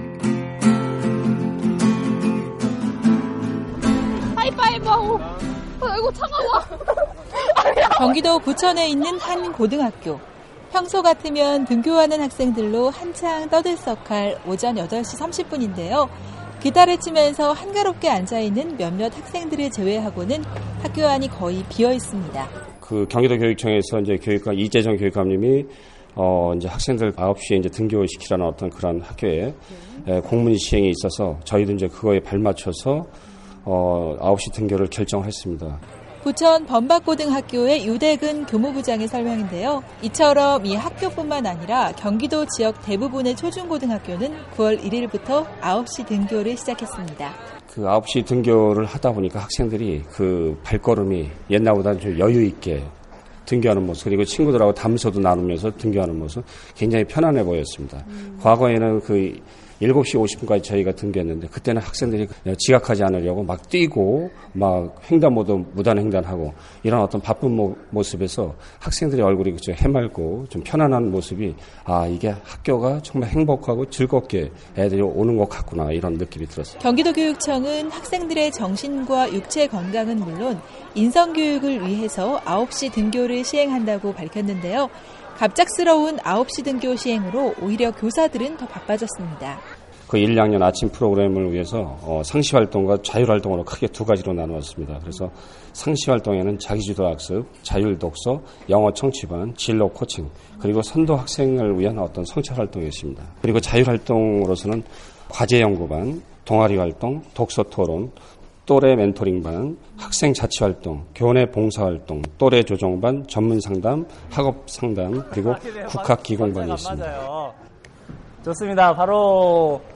경기도 부천의 한 고등학교를 찾아가봤습니다.